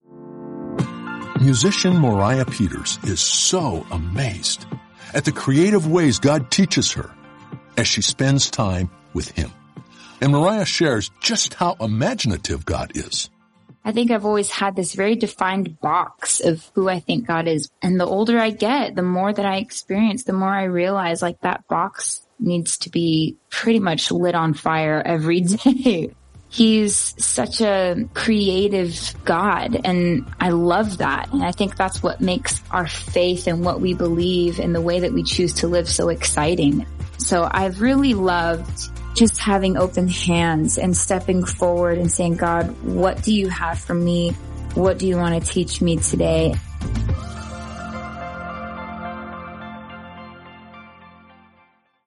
Moriah Peters talks about God being a creative teacher who desires to teach us to learn to walk with Him.
Each day, you’ll hear a short audio message with simple ideas to help you grow in your faith.